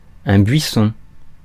Ääntäminen
France: IPA: [bɥi.sɔ̃]